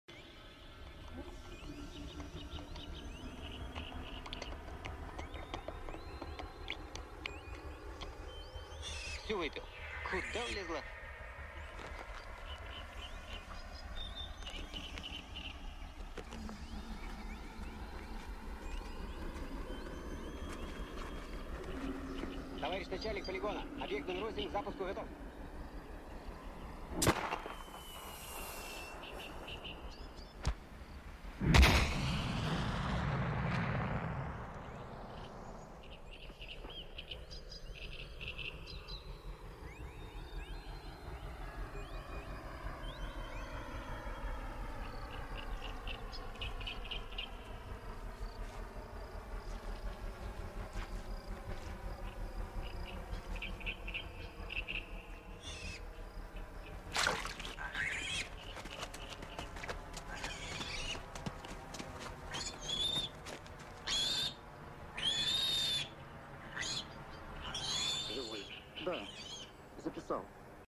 It s a scene from a Russian comedy movie in 2006 - called, "The First on the Moon".